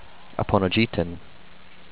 ah-poh-no-JEE-tun